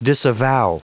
Prononciation du mot disavow en anglais (fichier audio)
Prononciation du mot : disavow